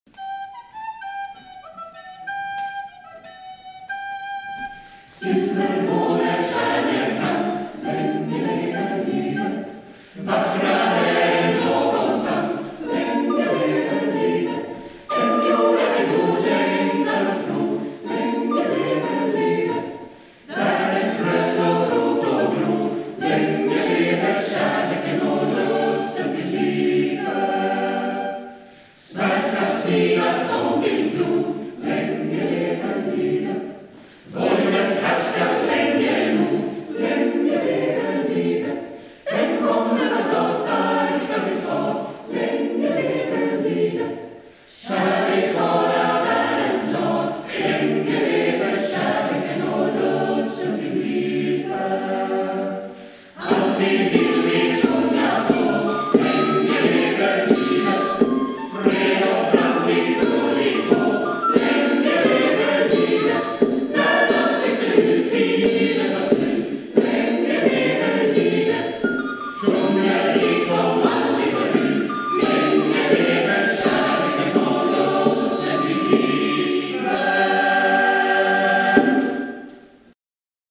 Sommarkonsert 2019 Vikens kyrka